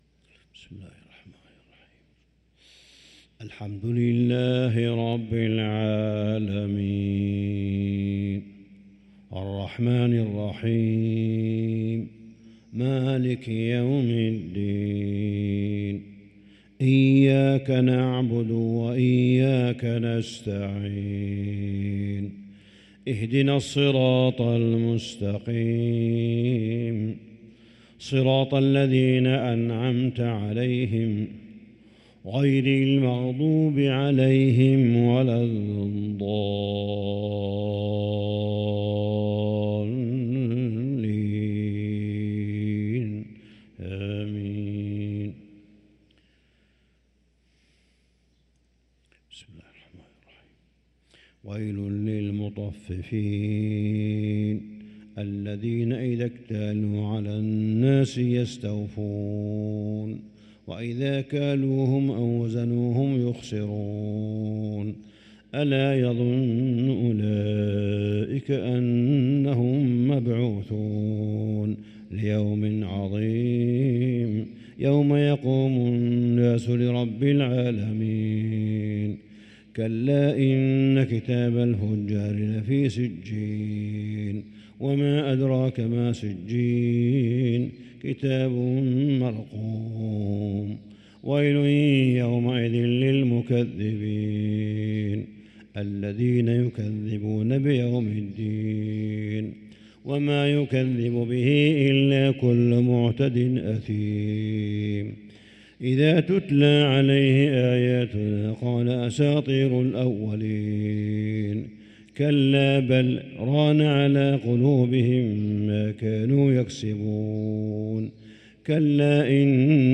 صلاة الفجر للقارئ صالح بن حميد 21 ربيع الآخر 1445 هـ
تِلَاوَات الْحَرَمَيْن .